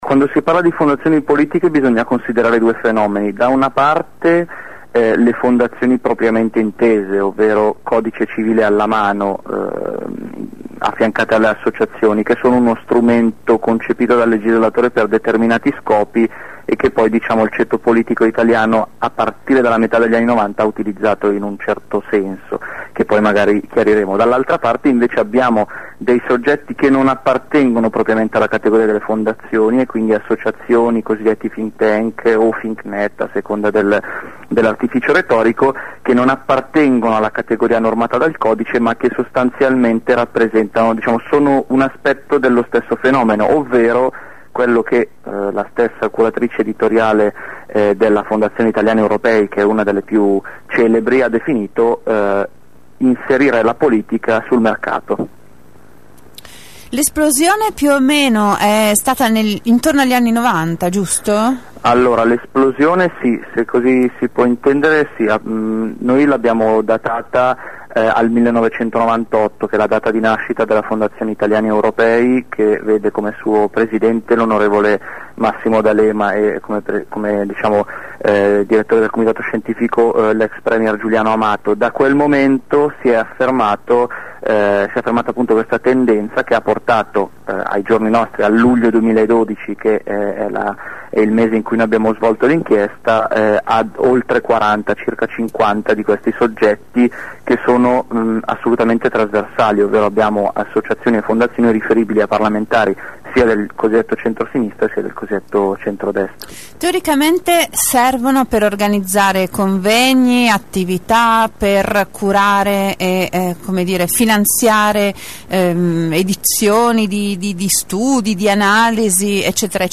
La video inchiesta di Altra Economia sulle fondazioni e le associazioni guidate da politici. L’intervista